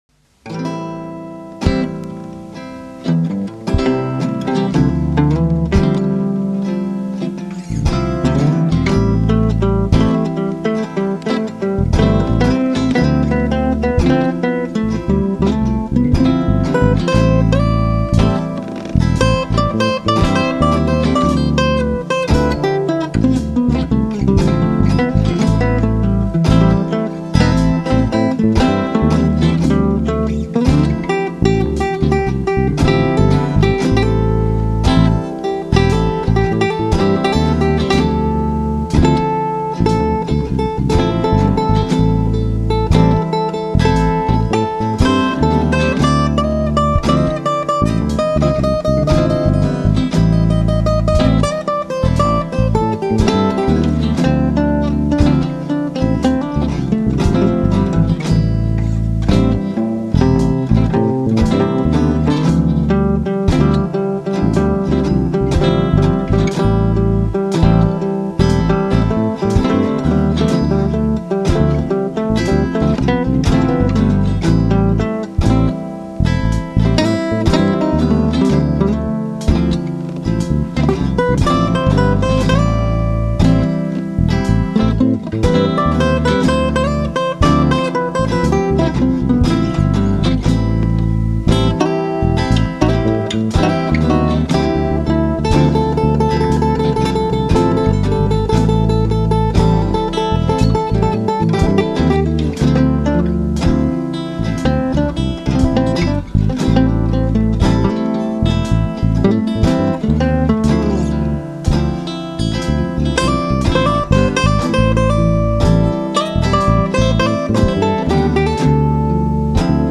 It's acoustic guitar sounds, so sit back and relax...